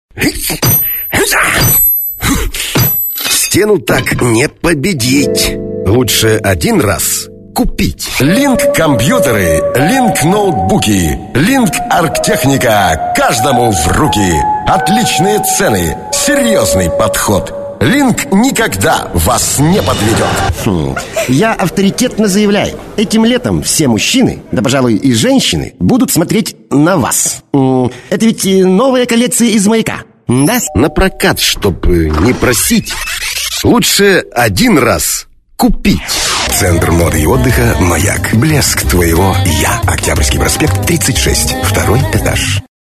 Рекламные ролики, компьютерные игры, пародии, радио-бренд войс График работы: с 8-00 до 22-00 мск.
Тракт: Октава МК-319А,FastTrackPro